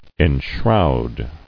[en·shroud]